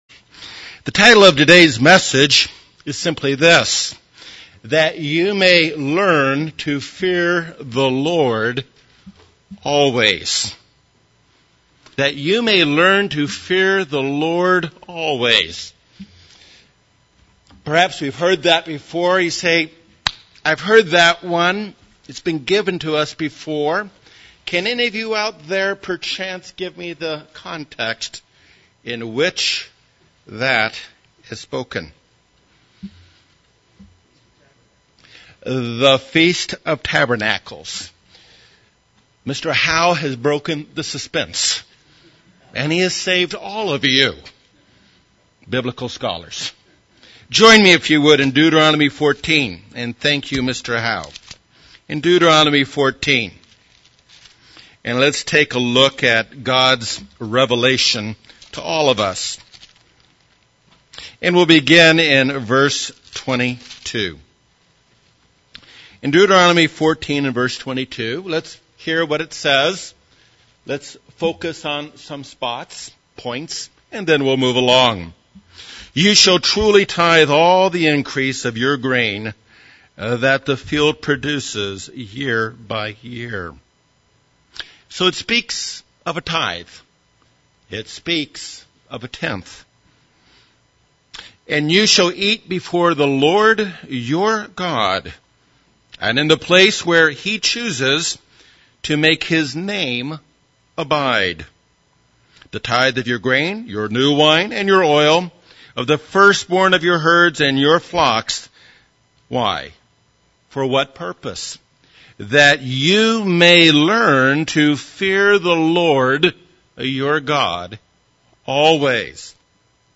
An excellent, detailed sermon on the festival tithe (2nd tithe).